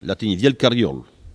Elle provient de Saint-Jean-de-Monts.
Locution ( parler, expression, langue,... )